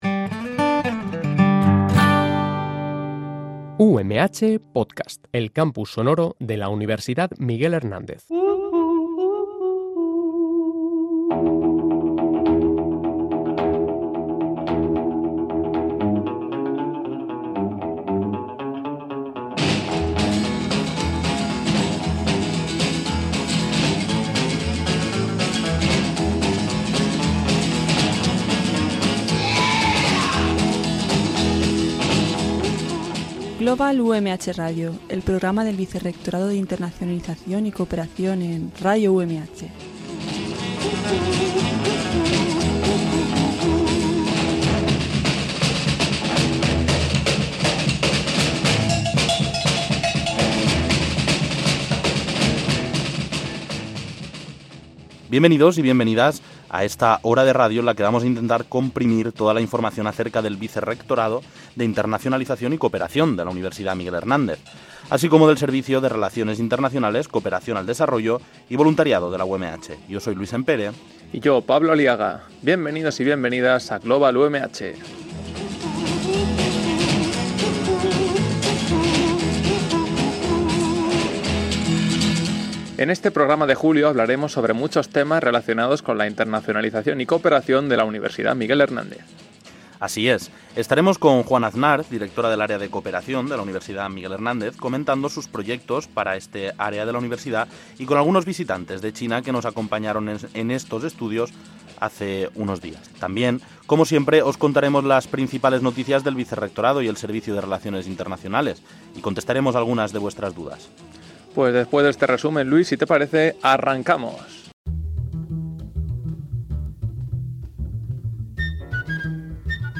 Bienvenidas y bienvenidos al décimo programa de la novena temporada de Global UMH Radio donde repasamos la actualidad del último mes del Vicerrectorado de Internacionalización y Cooperación de la UMH, en este caso, a través de una entrevista con representantes de la universidad china de Zhejiang .